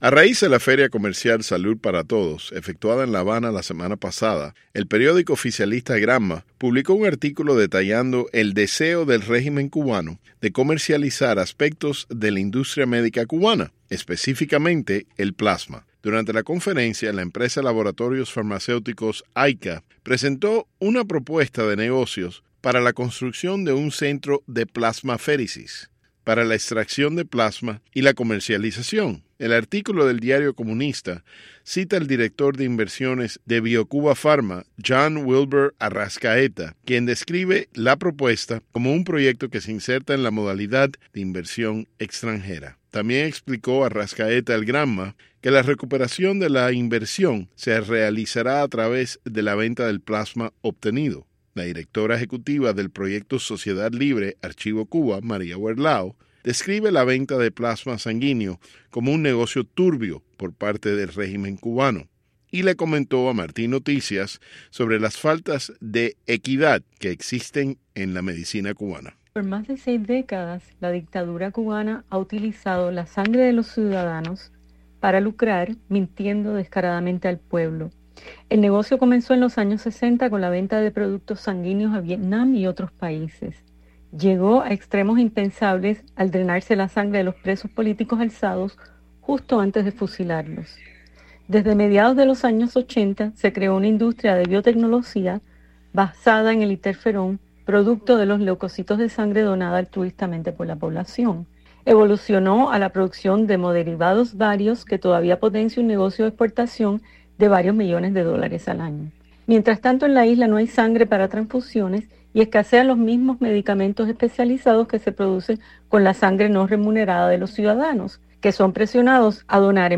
En entrevista con Martí Noticias, la activista aseguró que “durante más de seis décadas, la dictadura cubana ha utilizado la sangre de sus ciudadanos para lucrarse, mintiendo descaradamente al pueblo”.